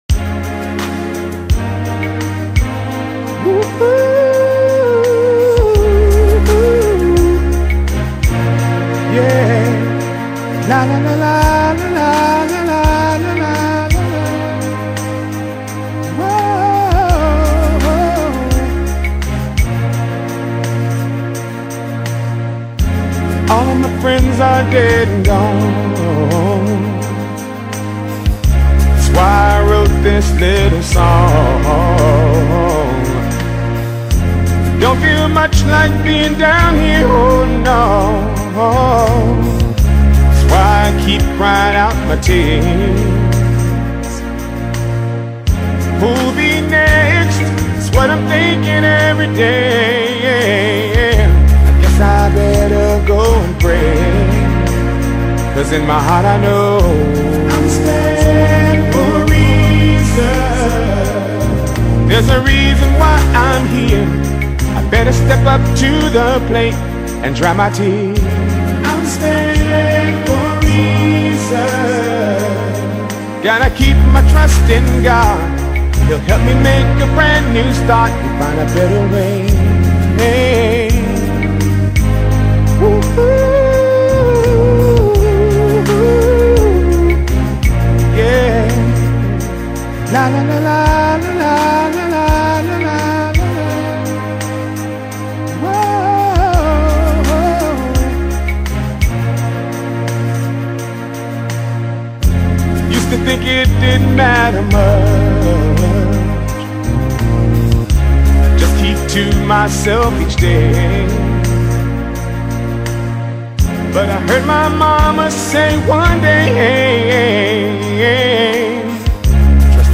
VOCALIST
/PIANIST/SONGWRITER
A Classic Gospel Compilation